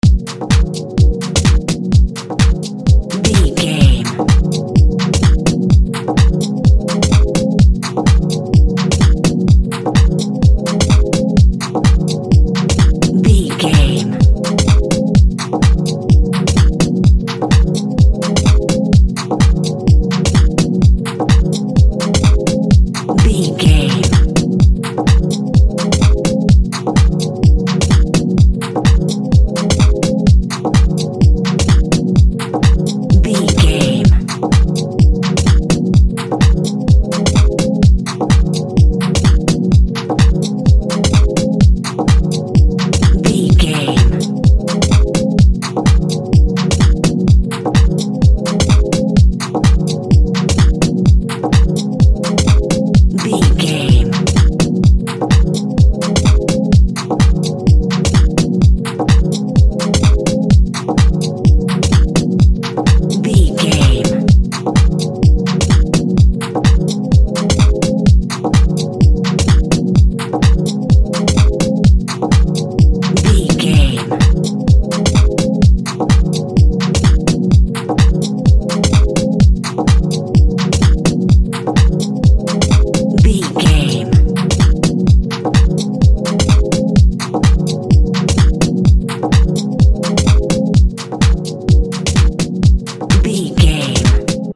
Fusion Club Music.
Aeolian/Minor
E♭
groovy
smooth
futuristic
drum machine
synthesiser
Drum and bass
break beat
electronic
sub bass
synth lead
synth bass